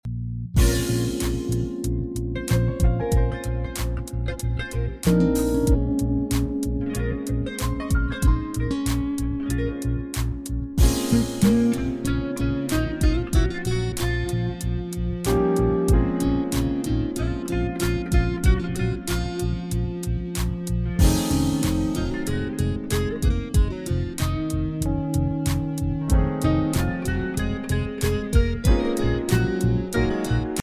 Arranged & Lead Guitar
Bass Guitar